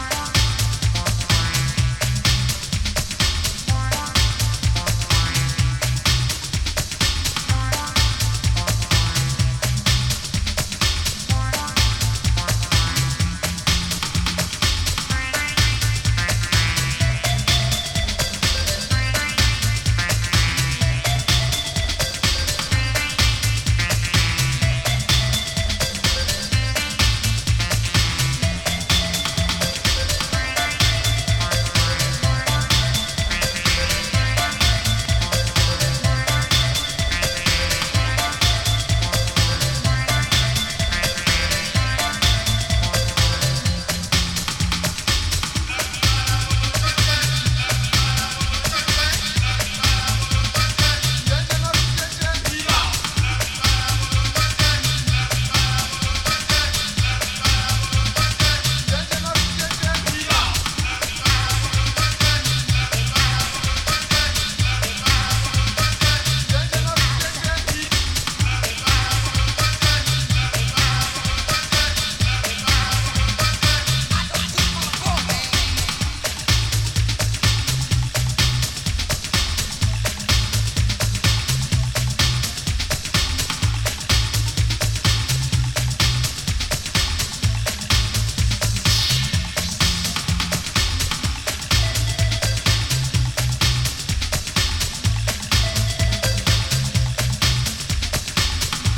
New Rootsを経てオリジナルなダンス・ミュージック・スタイルへ変わっていく素晴らしい作品集。